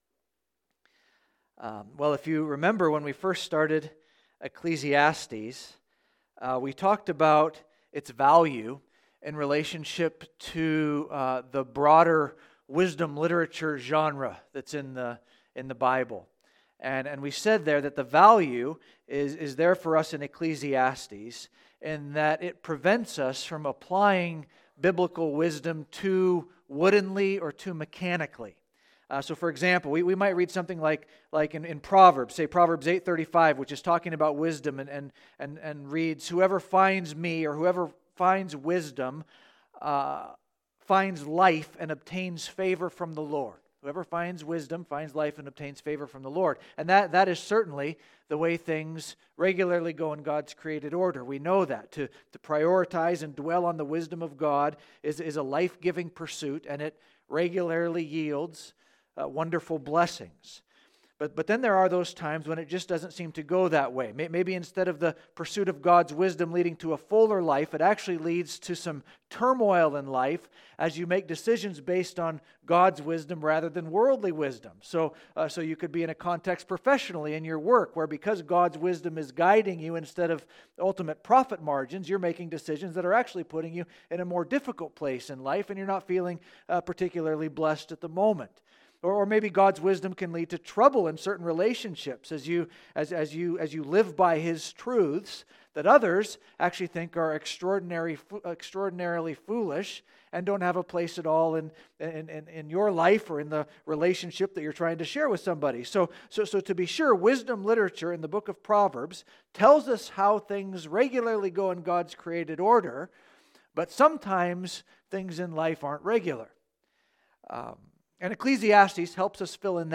Listen to weekly sermons from Christ Church Sellwood in Portland, Ore.